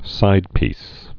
(sīdpēs)